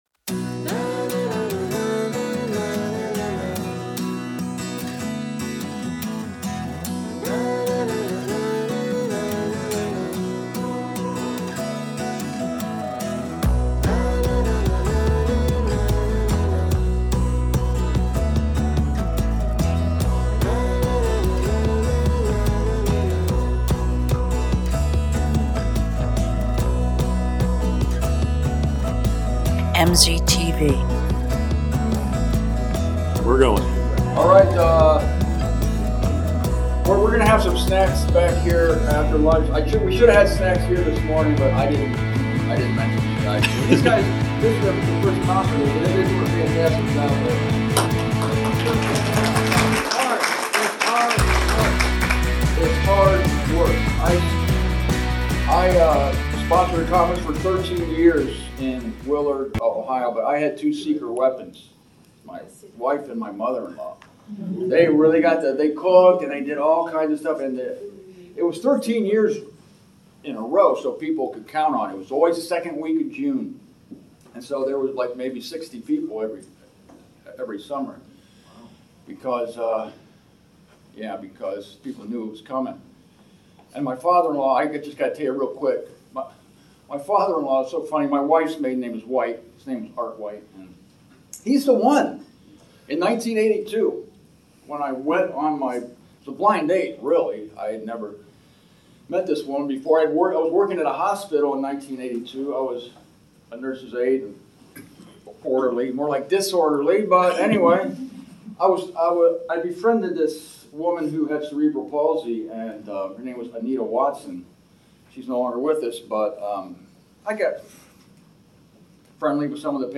MZTV 1003: Richmond Conference